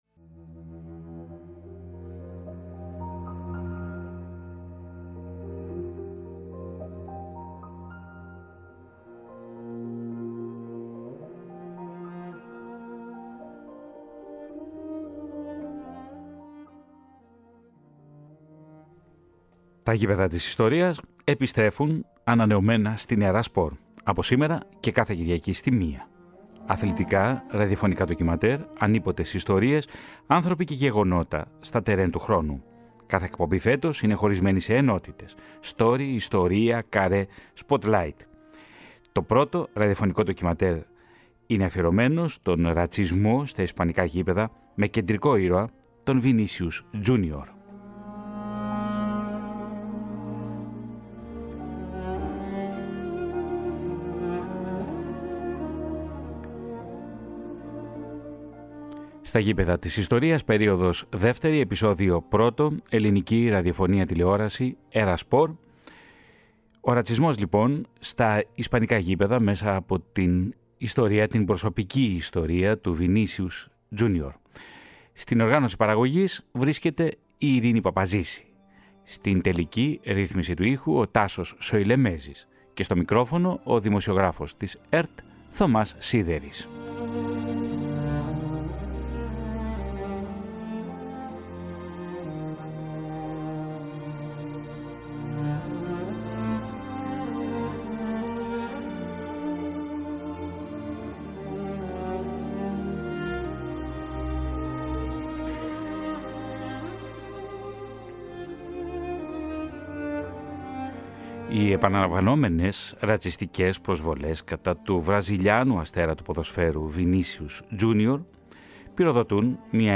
Στο ραδιοφωνικό ντοκιμαντέρ και με αφορμή την περίπτωση του Βινίσιους Τζούνιορ, ερευνώνται οι πολλαπλές όψεις του ρατσισμού στα ευρωπαϊκά γήπεδα.